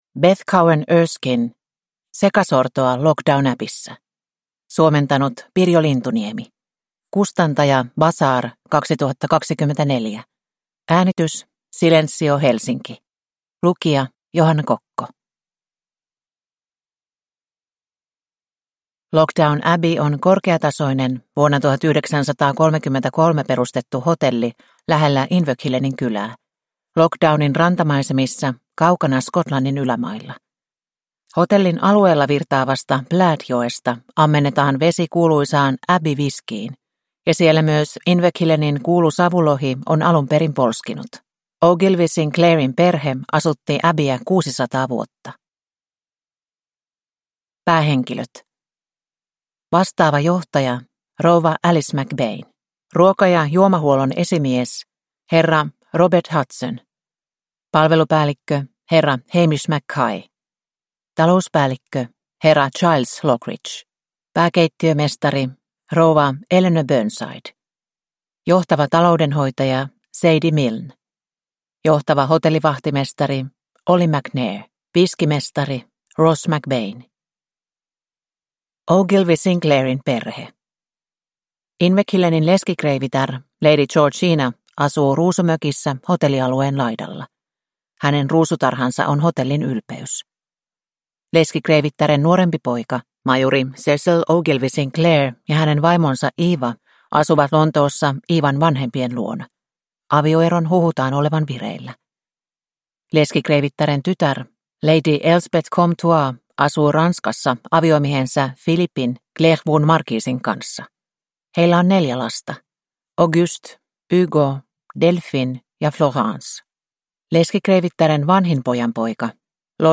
Sekasortoa Loch Down Abbeyssa (ljudbok) av Beth Cowan-Erskine